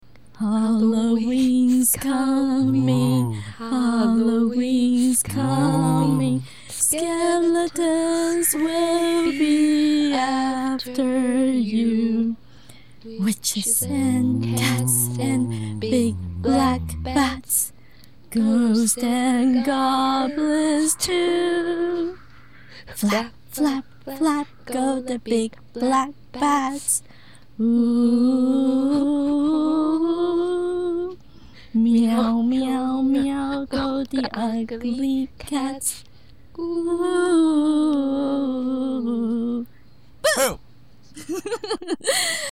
This is the most classical song in Halloween, American children like to sing in Halloween.